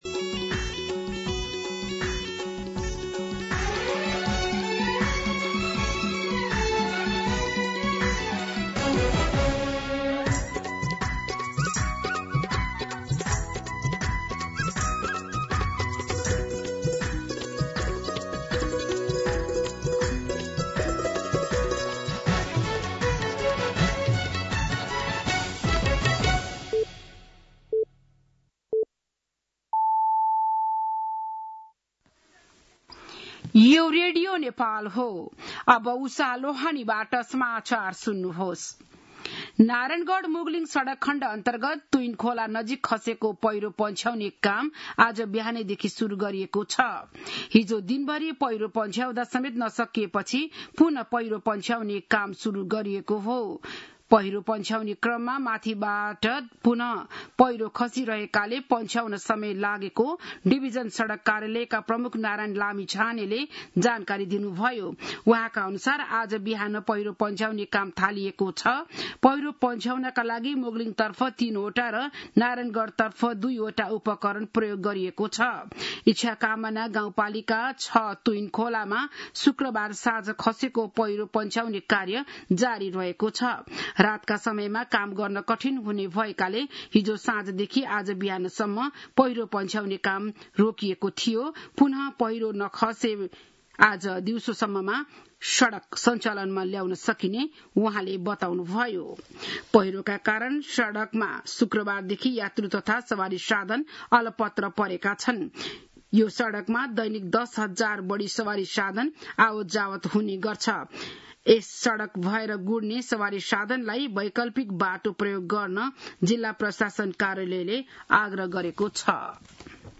बिहान ११ बजेको नेपाली समाचार : ५ असोज , २०८२